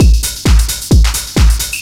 DS 132-BPM A1.wav